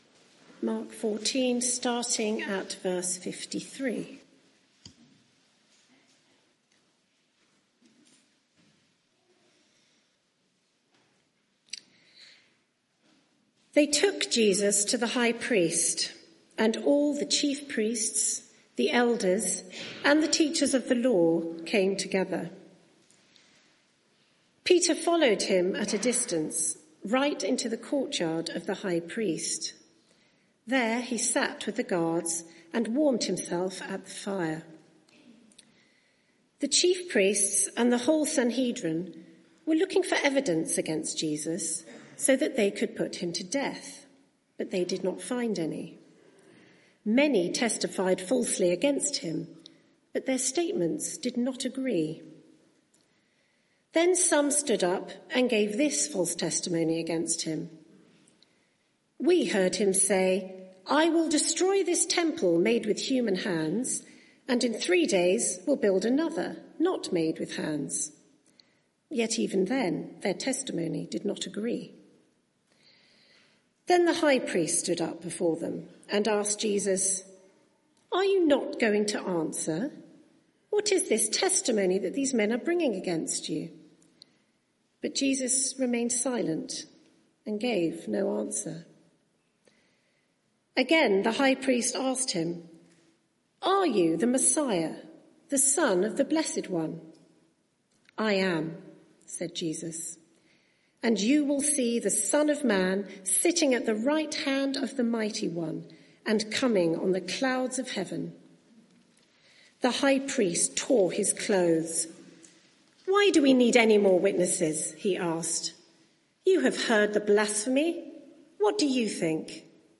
Media for 6:30pm Service on Sun 30th Mar 2025 18:30 Speaker
Sermon (audio) Search the media library There are recordings here going back several years.